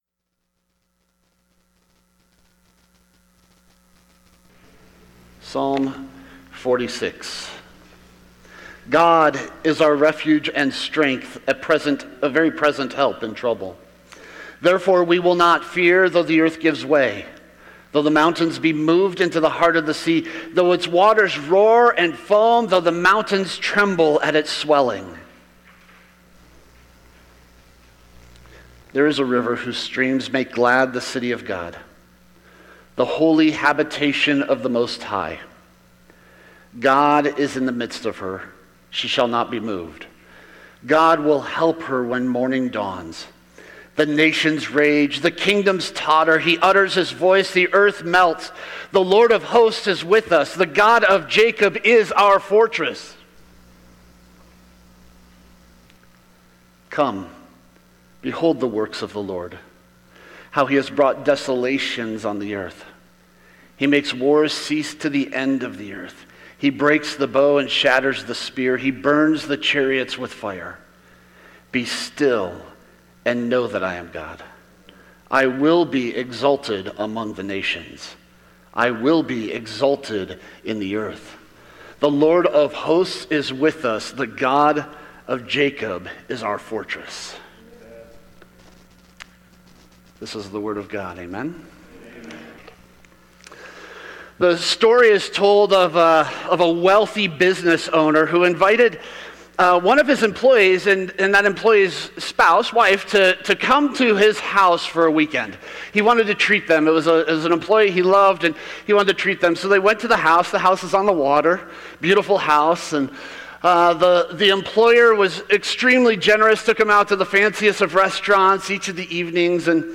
Sermons | The Rock of the C&MA